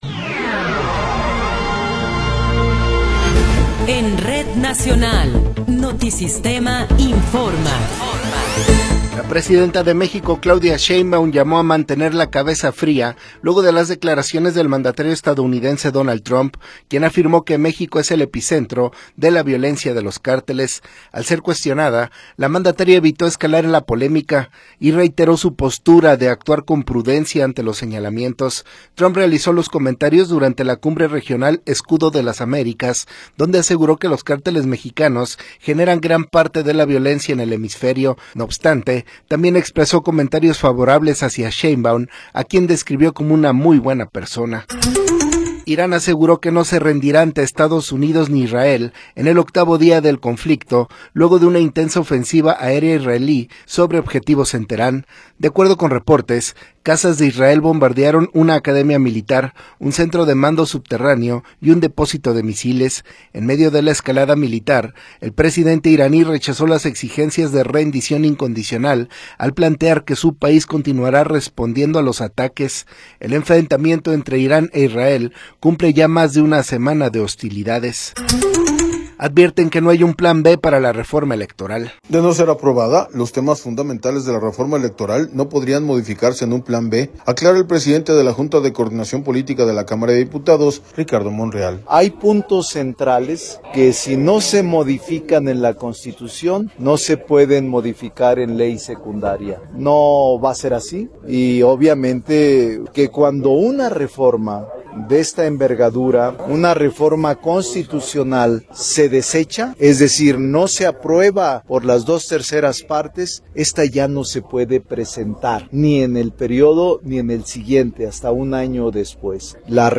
Noticiero 13 hrs. – 7 de Marzo de 2026
Resumen informativo Notisistema, la mejor y más completa información cada hora en la hora.